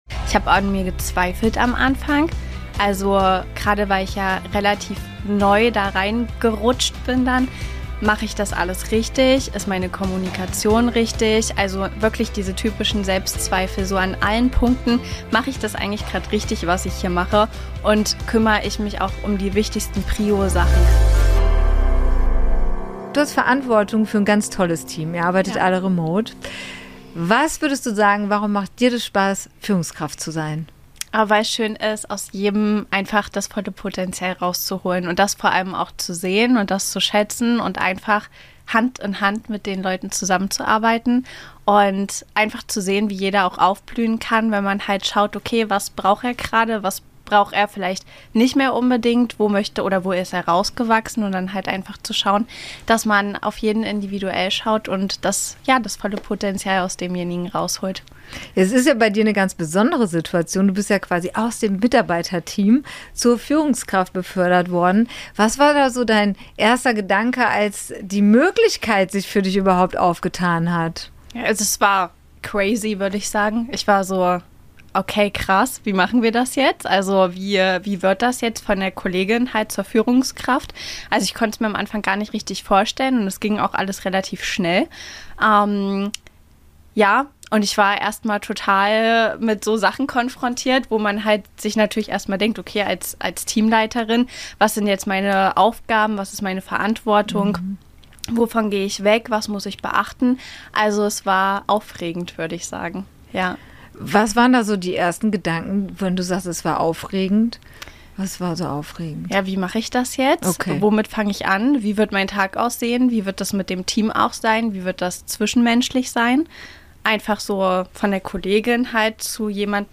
Gedreht wurde im Eventflugzeug auf dem euronova Campus in Hürth.